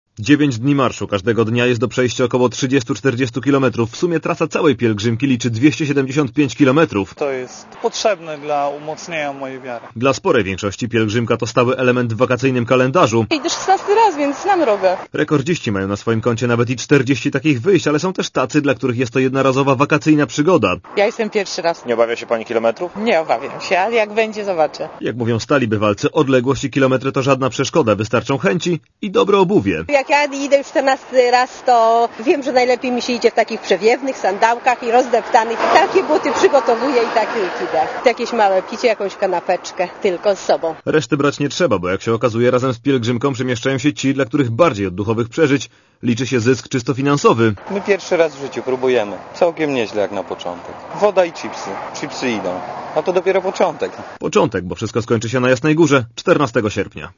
Z pielgrzymami rozmawiał reporter Radia Zet.